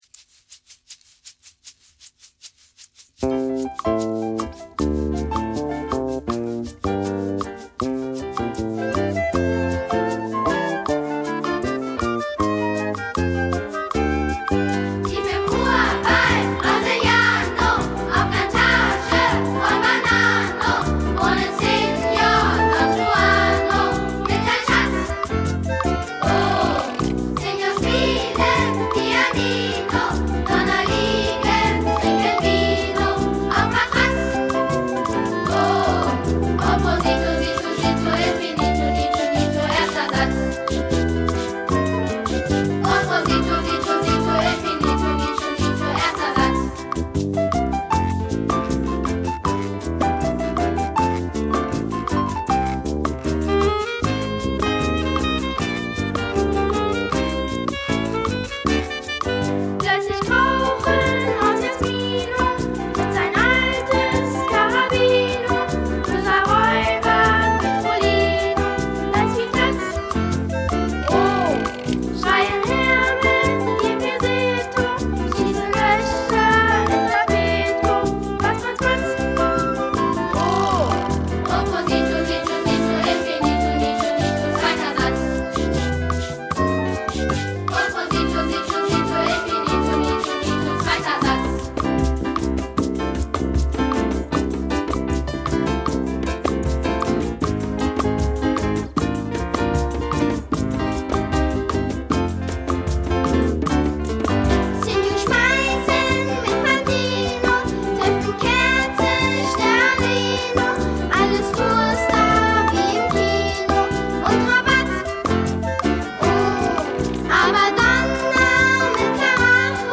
Lieder zum Spielen, Tanzen und Mitmachen